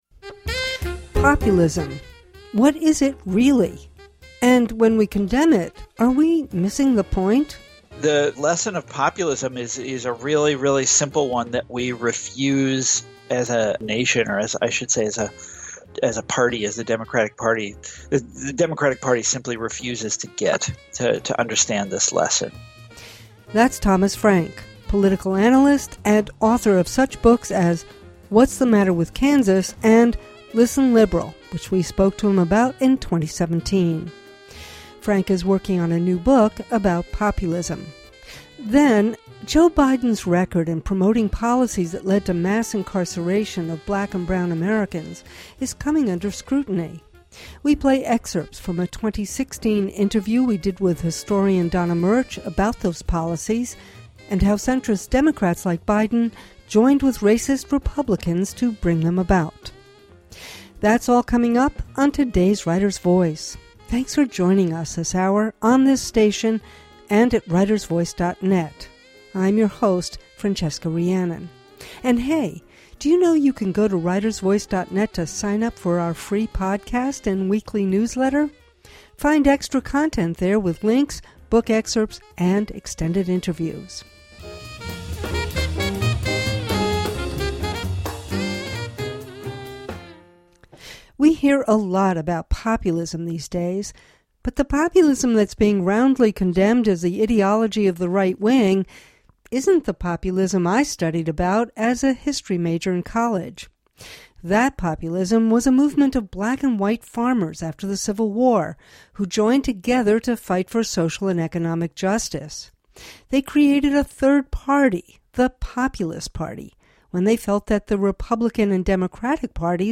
Writer’s Voice — in depth conversation with writers of all genres, on the air since 2004.